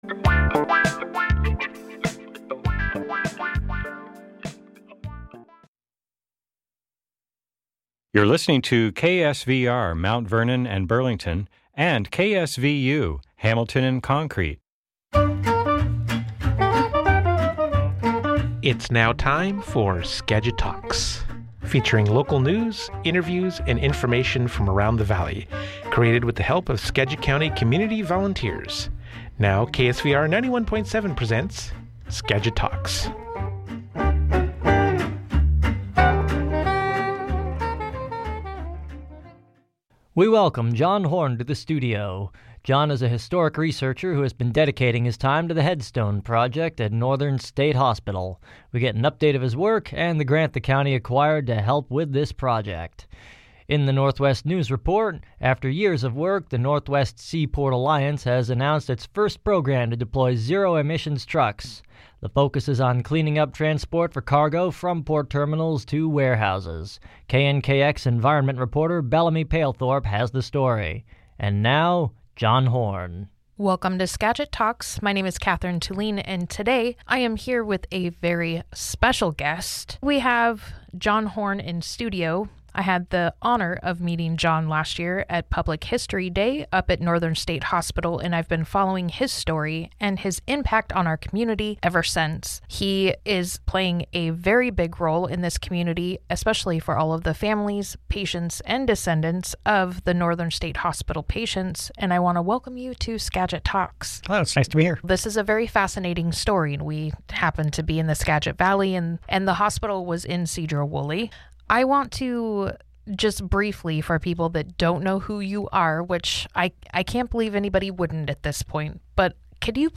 NSH CEMETERY - INTERVIEW ON KSVR